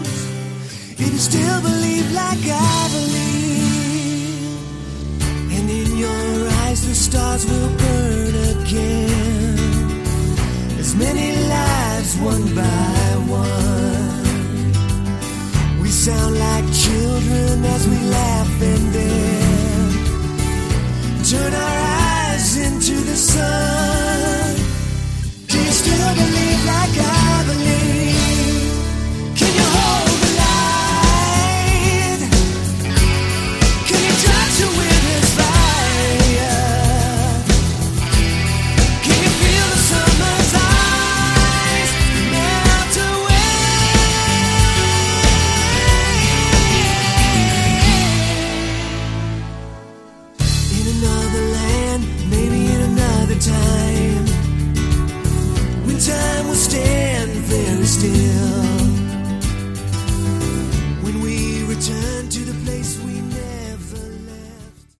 Category: AOR
vocals, guitars
guitars, keyboards, bass
drums, percussion